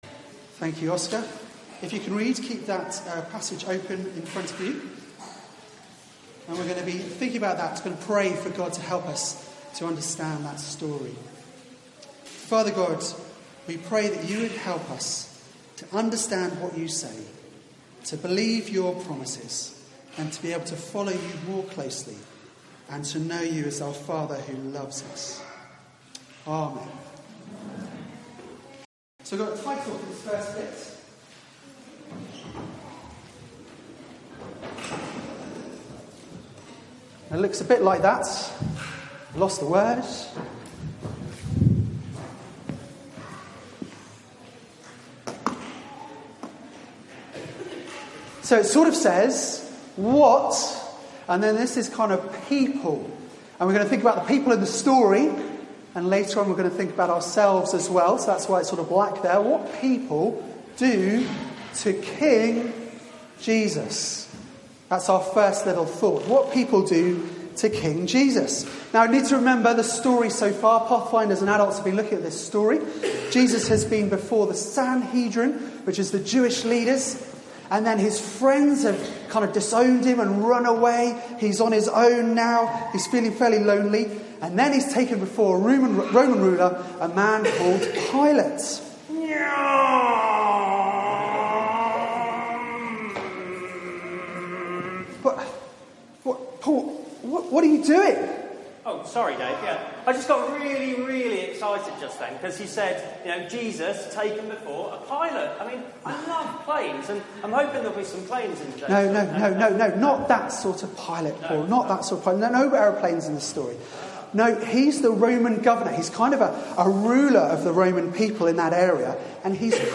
Media for 4pm Service on Sun 11th Mar 2018 16:00 Speaker